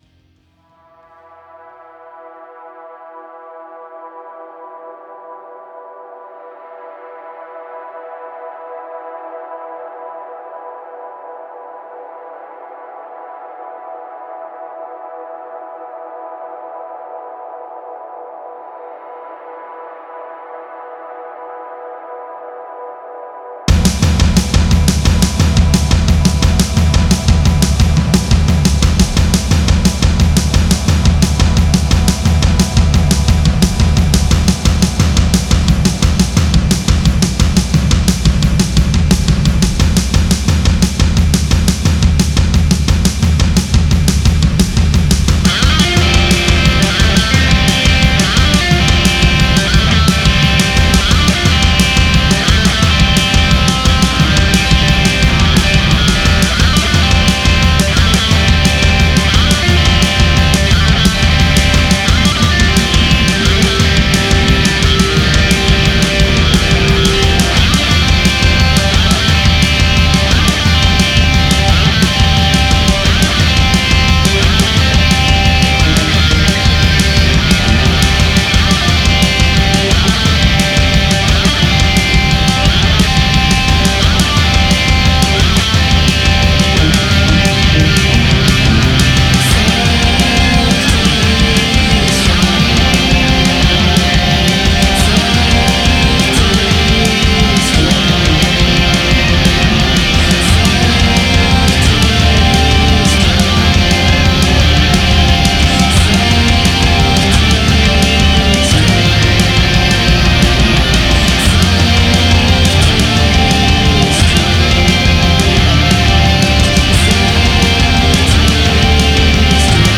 métal alambiqué et impressionnant